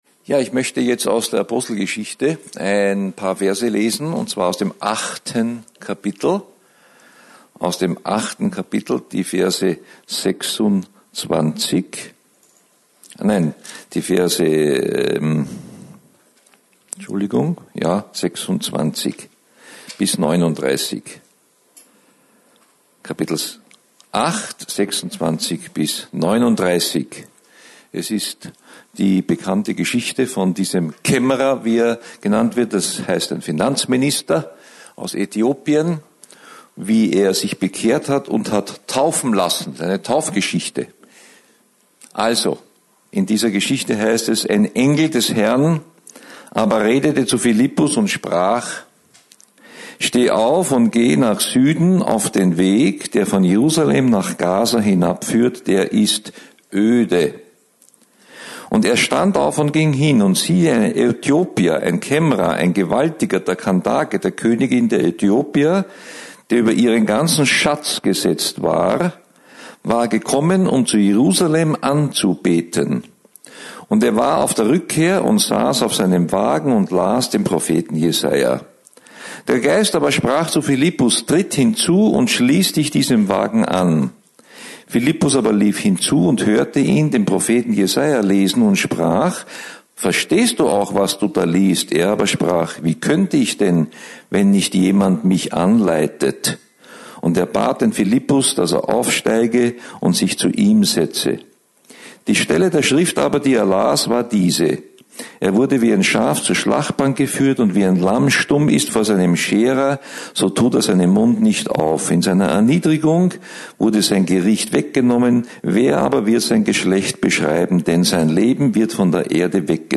Hier finden Sie das Predigt Archiv für das Jahr 2018.